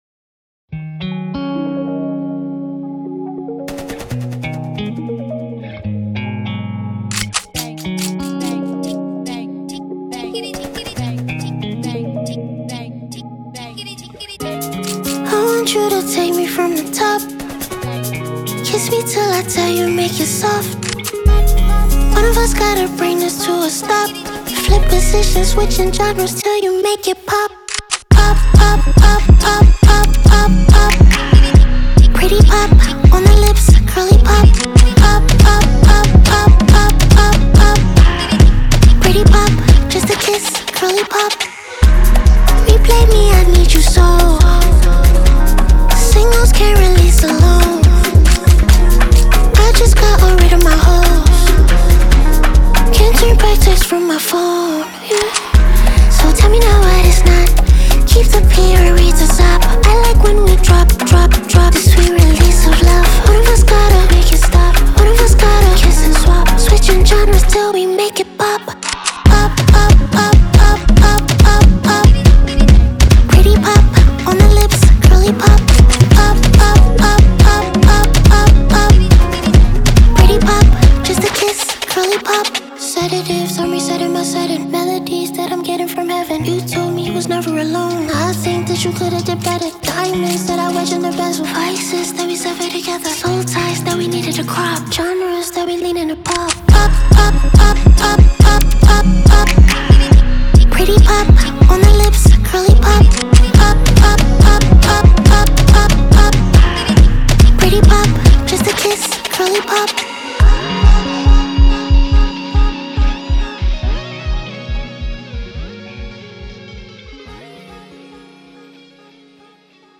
New song from Ghanaian-American singer